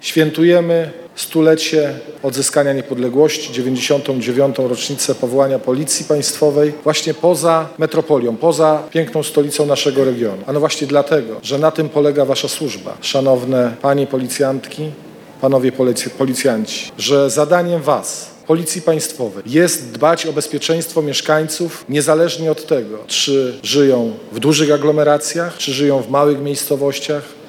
Na stargardzkim Rynku Staromiejskim zakończyły się uroczystości związane z tegorocznym obchodami Święta Policji.
– mówił szef resortu